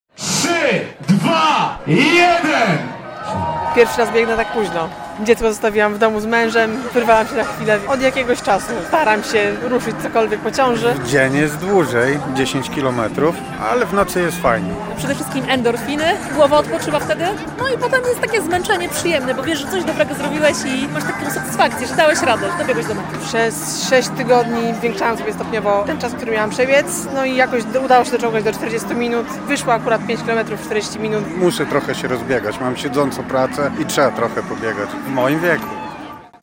Nocna Piątka na 13. Białystok Biega - relacja